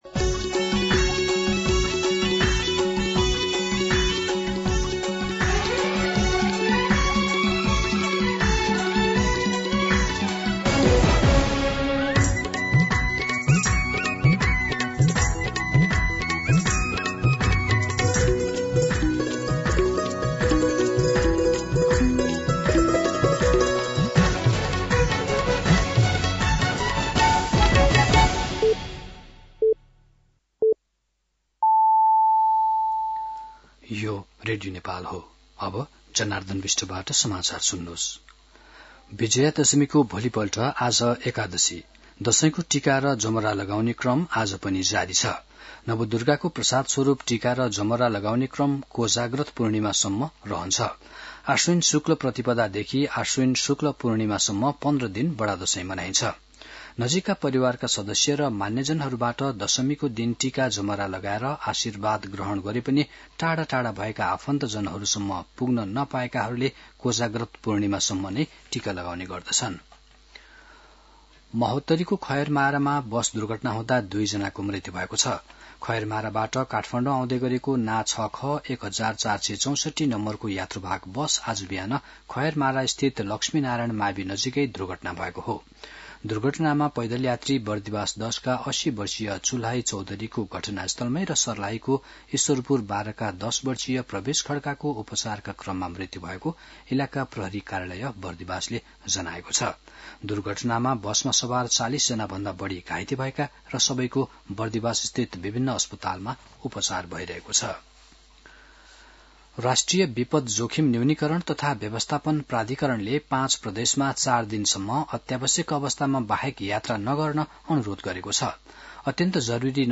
दिउँसो १ बजेको नेपाली समाचार : १७ असोज , २०८२